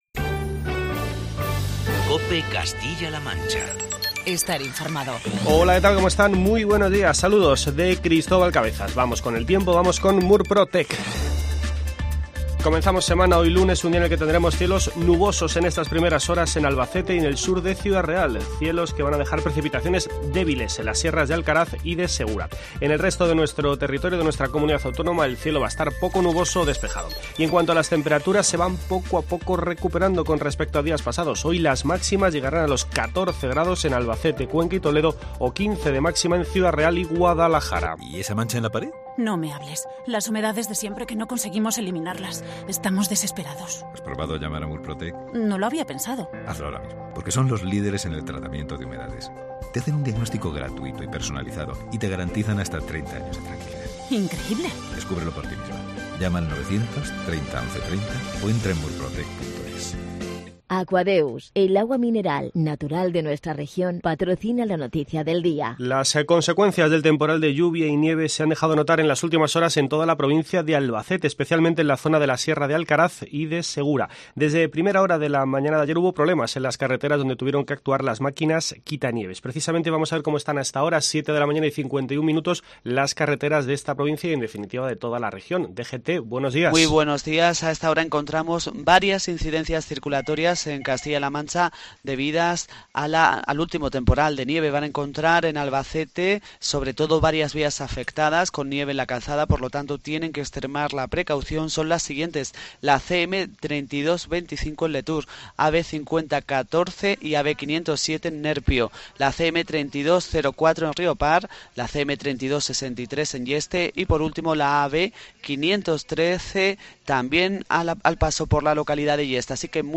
informativo matinal de COPE Castilla-La Mancha